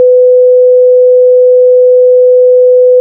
16bit_sine.ogg